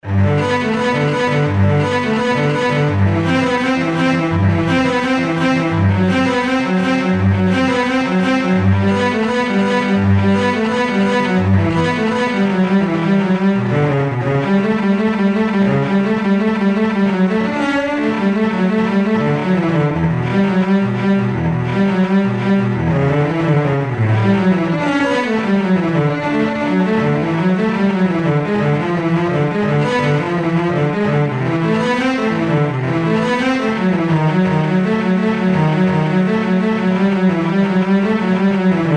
無伴奏チェロはチェロ一台で演奏する独奏曲集で、第1番から第6番まであり、そのうちのプレリュード（前奏曲）です。
クラシック